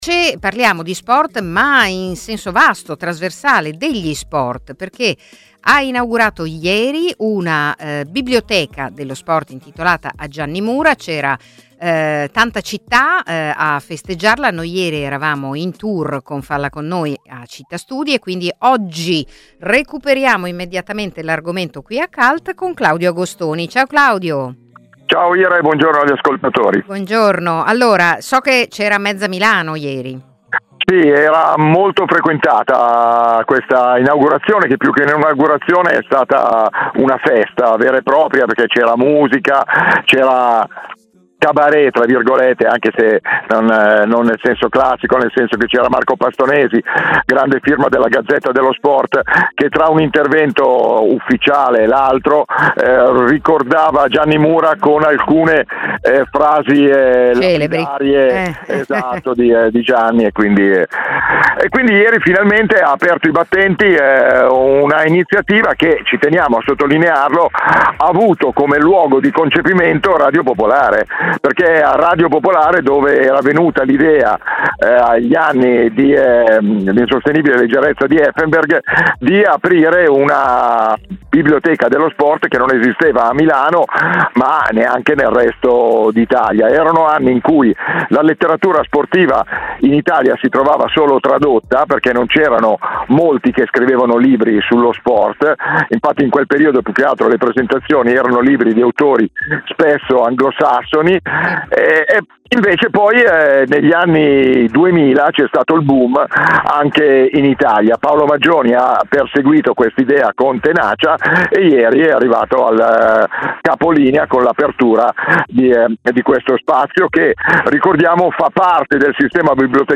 è andato all'inaugurazione, ce l'ha raccontata e ha intervistato Anita Pirovano, Presidente del Municipio 9.